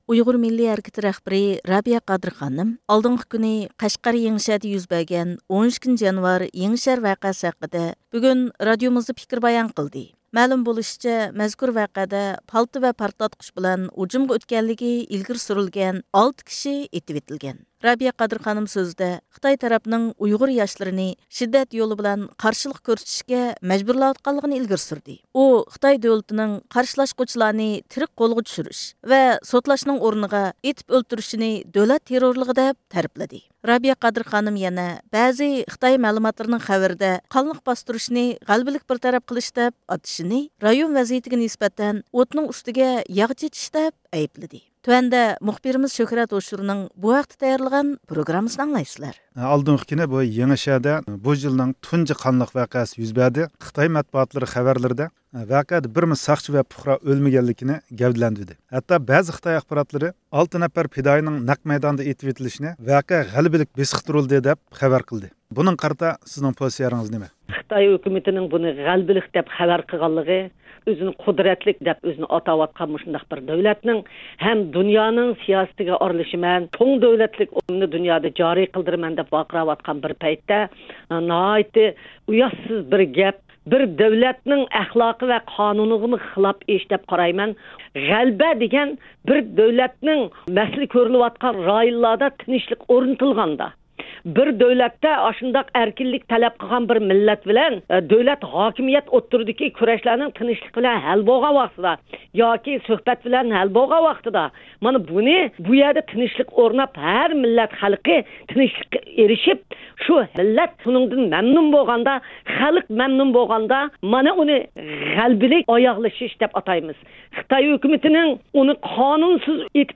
ئۇيغۇر مىللىي ھەرىكىتى رەھبىرى رابىيە قادىر خانىم، ئالدىنقى كۈنى قەشقەردە يۈز بەرگەن، بۇ يىلقى تۇنجى قانلىق ۋەقە – «12-يانۋار يېڭىشەھەر ۋەقەسى» ھەققىدە بۈگۈن رادىئومىزدا پىكىر بايان قىلدى.